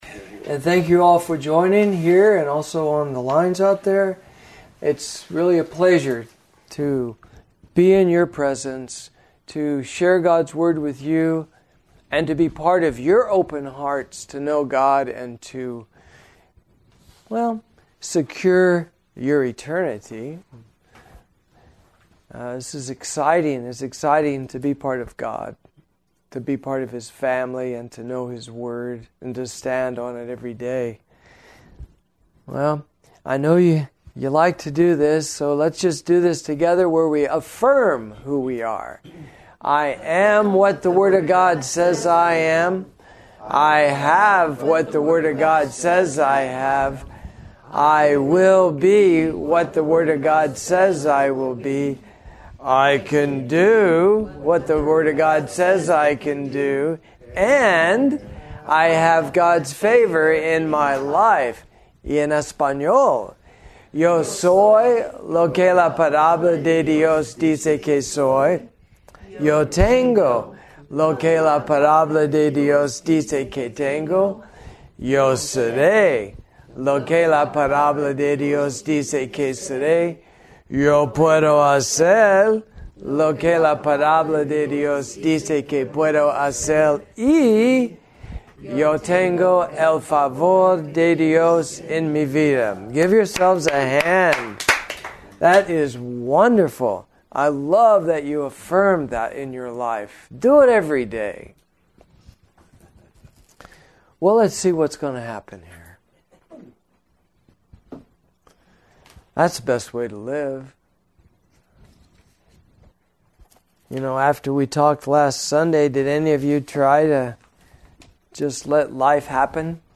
You will hear a musical tone during the teaching to let you know when to play the video